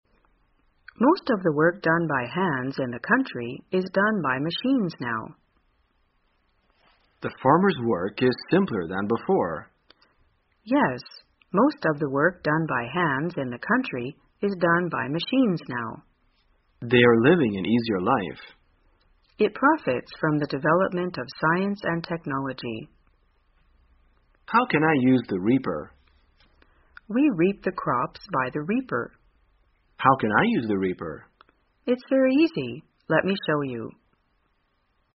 在线英语听力室生活口语天天说 第314期:怎样谈论农村工作的听力文件下载,《生活口语天天说》栏目将日常生活中最常用到的口语句型进行收集和重点讲解。真人发音配字幕帮助英语爱好者们练习听力并进行口语跟读。